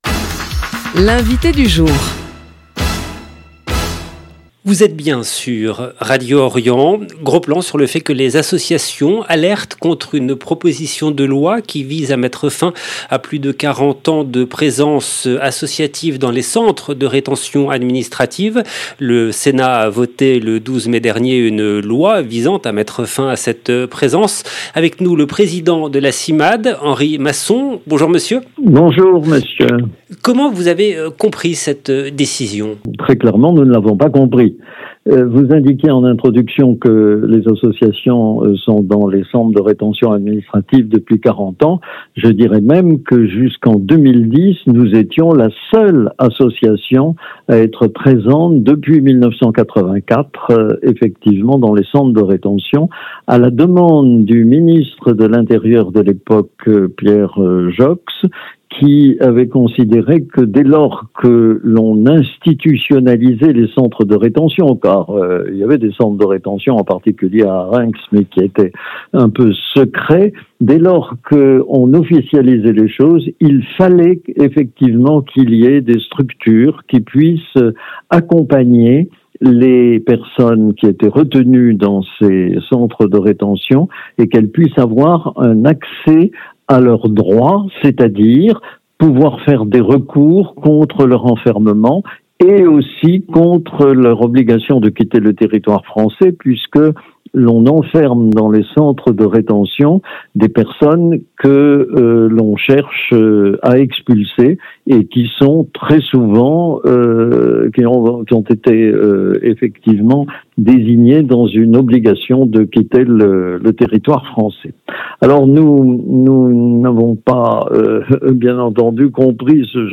L’invité du jour du mardi 3 juin 2025